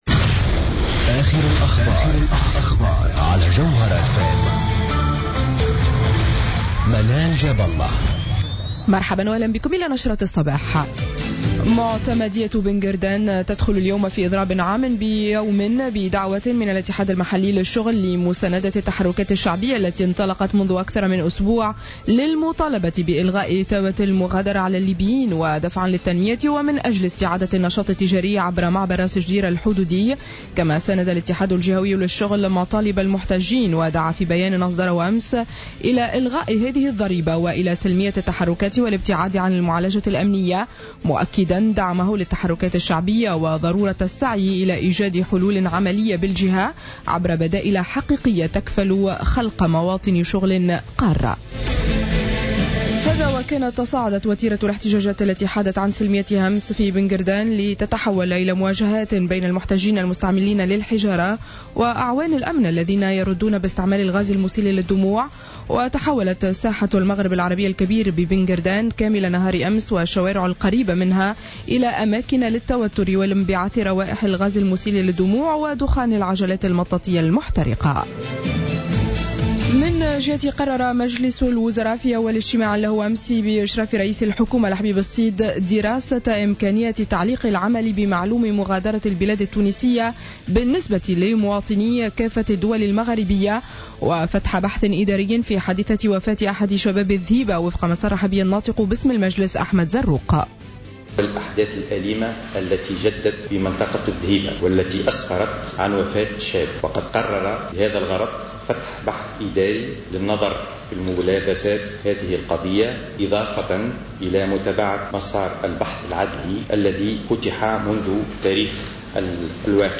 نشرة أخبار السابعة صباحا ليوم الثلاثاء 10 فيفري 2015